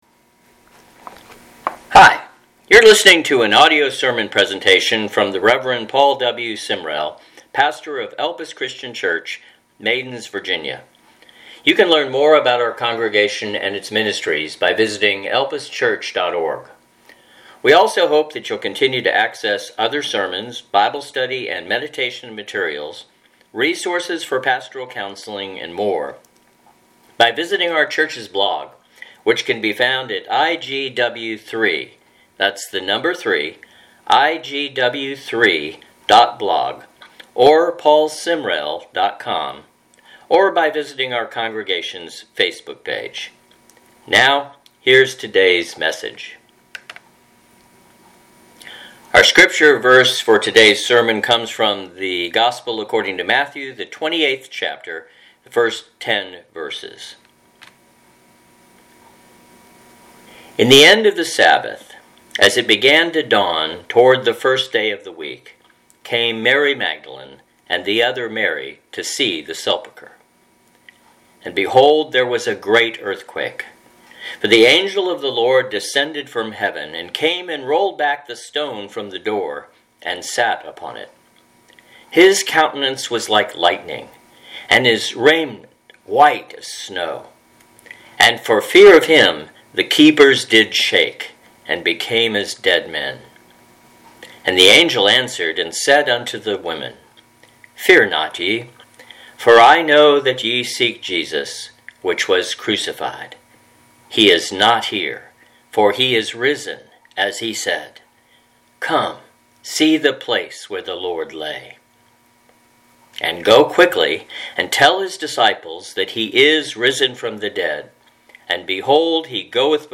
Audio Sermon for April 12, 2020 – “As It Began to Dawn” - IGW3 - In God's Word, In God's Will, In God's Work